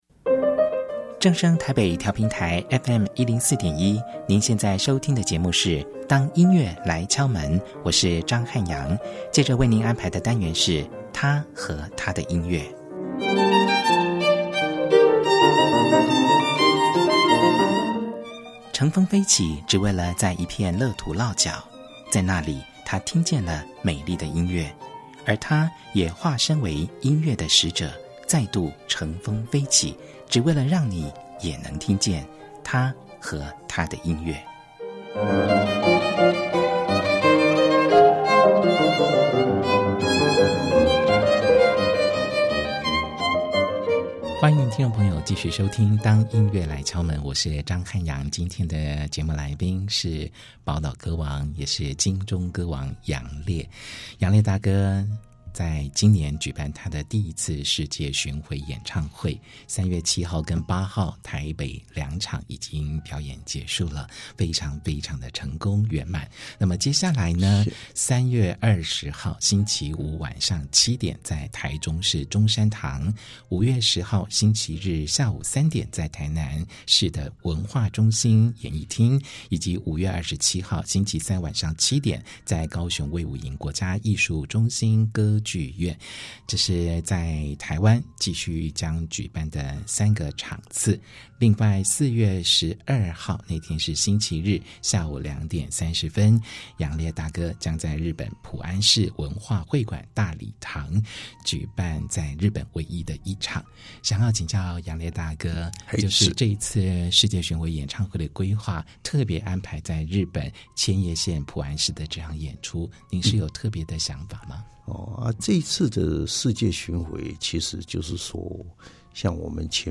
第一單元~本集節目來賓是金鐘歌王楊烈。